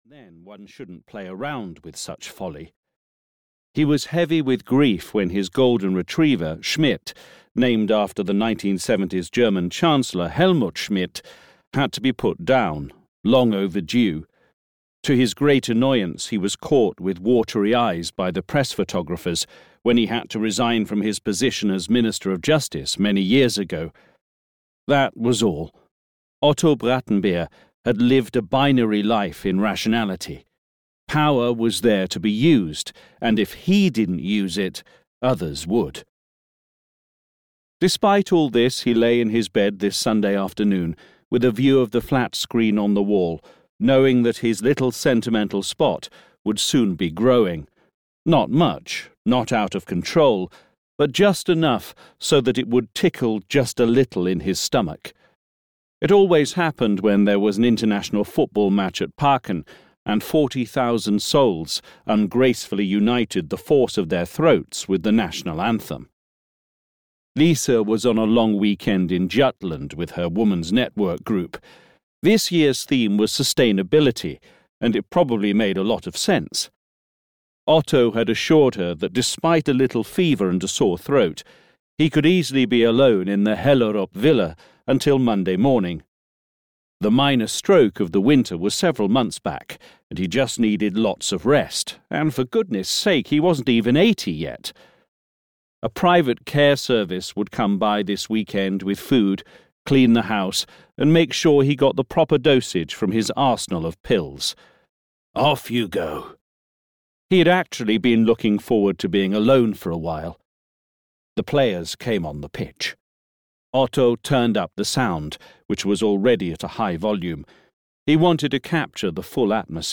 Devil's Democracy (EN) audiokniha
Ukázka z knihy